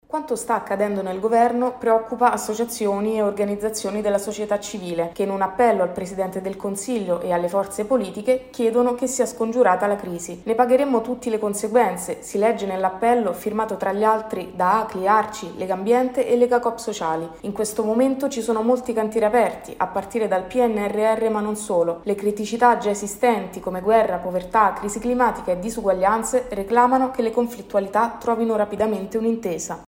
Csvnet e Forum terzo settore plaudono al bando del Miur che coinvolge il non profit nelle scuole ma serve più tempo. Il servizio